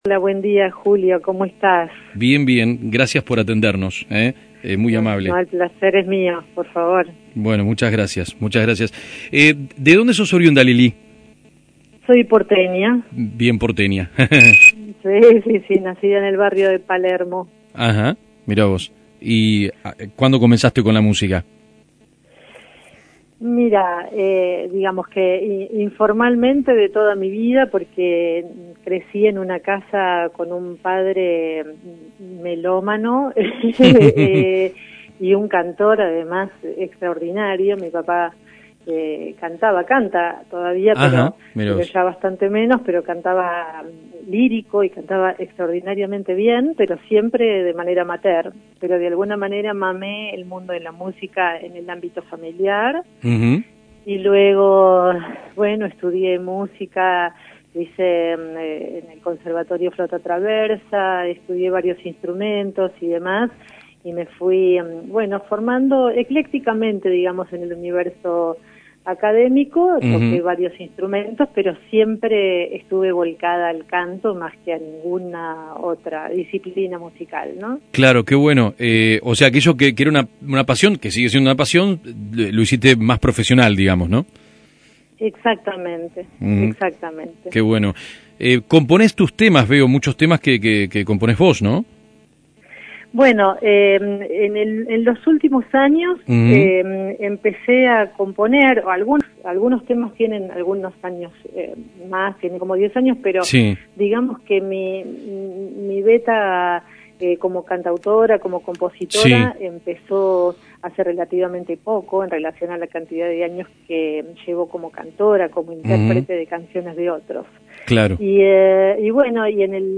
Música y poética con vuelo propio(Audio Nota)